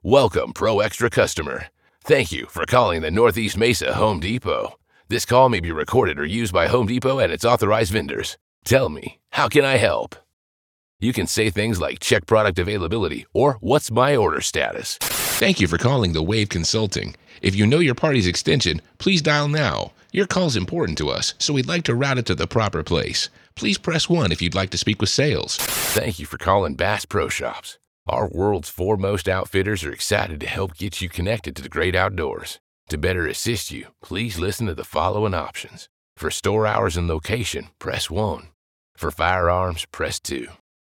Natural, Llamativo, Accesible, Versátil, Amable
Telefonía